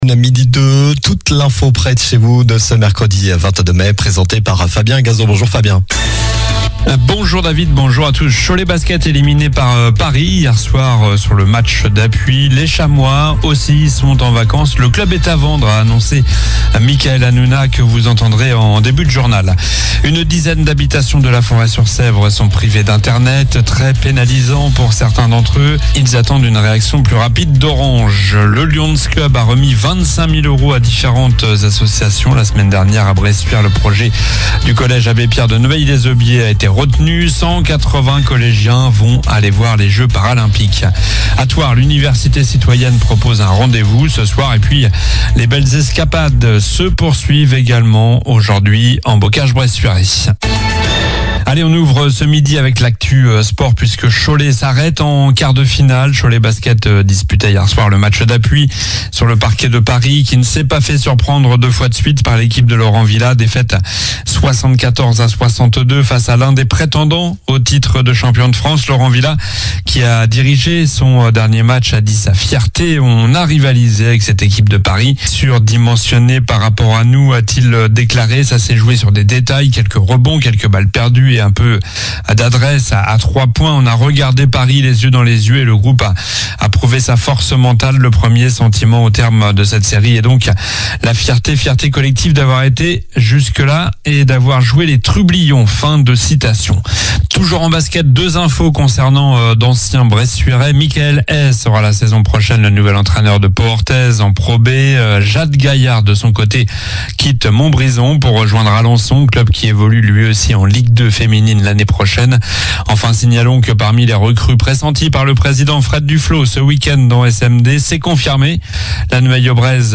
Journal du mercredi 22 mai (midi)